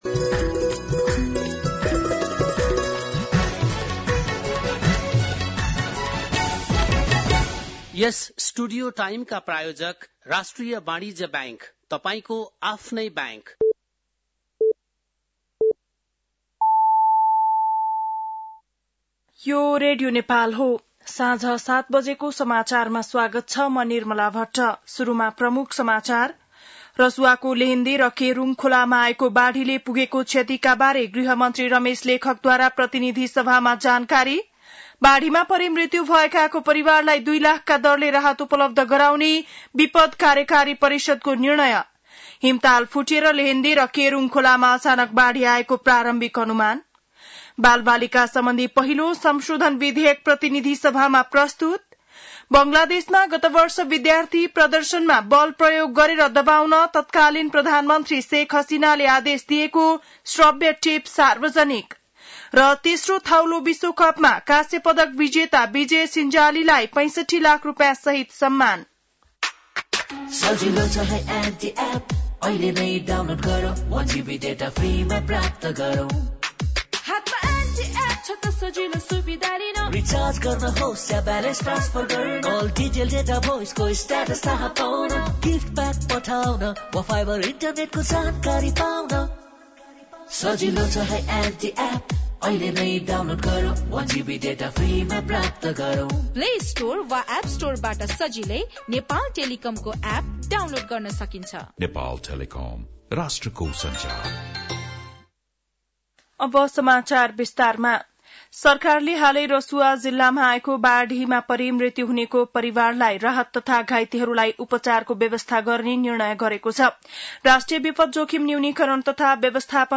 बेलुकी ७ बजेको नेपाली समाचार : २५ असार , २०८२